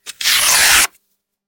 Flesh Tear
A disturbing flesh tearing sound with wet ripping and stretching fibers
flesh-tear.mp3